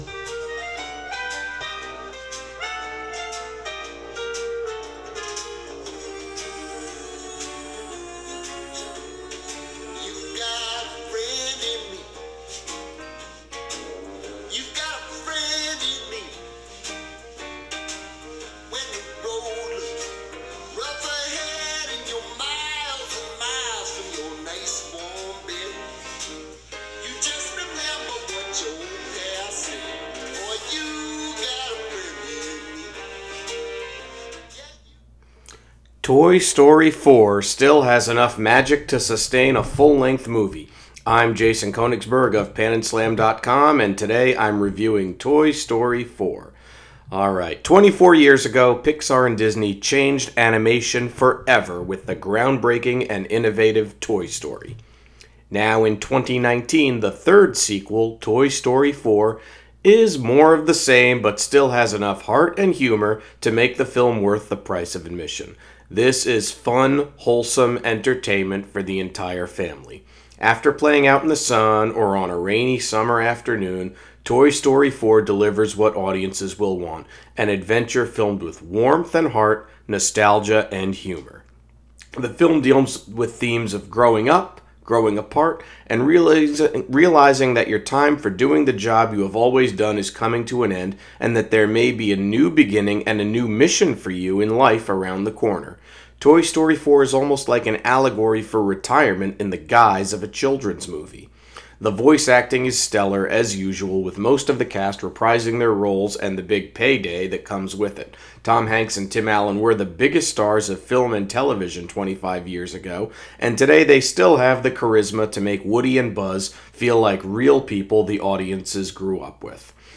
Movie Review: Toy Story 4